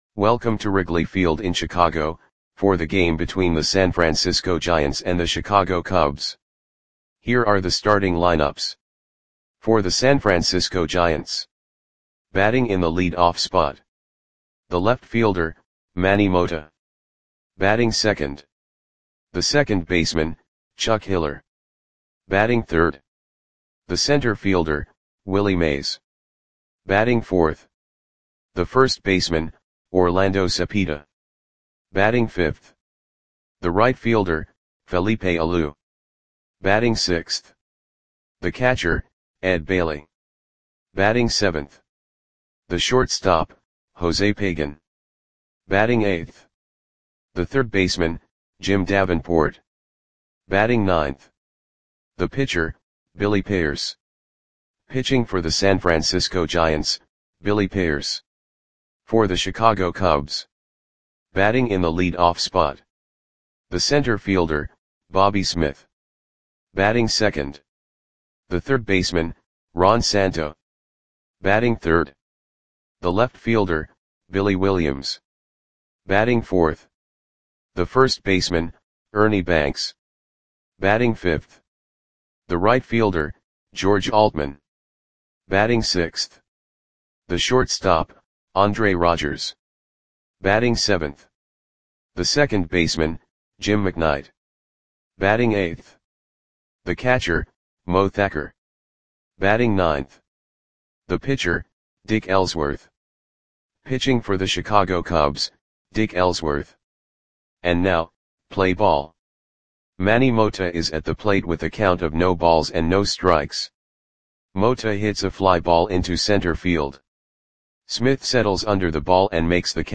Audio Play-by-Play for Chicago Cubs on May 4, 1962
Click the button below to listen to the audio play-by-play.